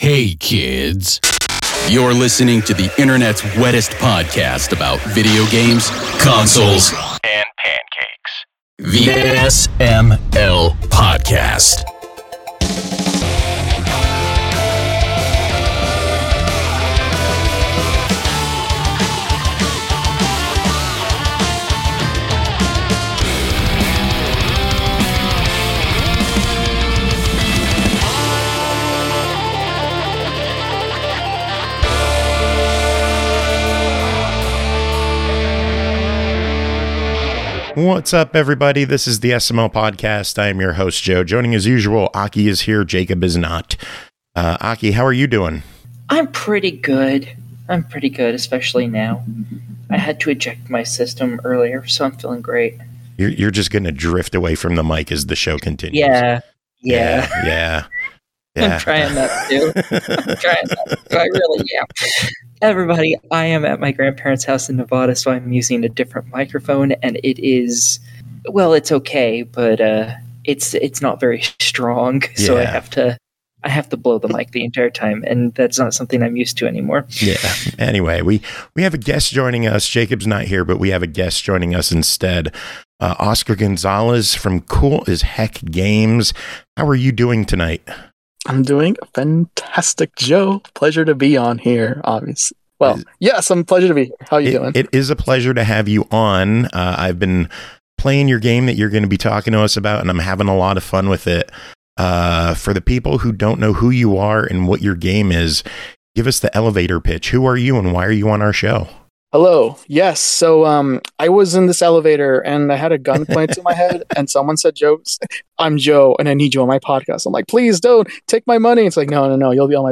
We’ve got the Game Awards wrapup, reviews, and the shortest interview in SML history coming up!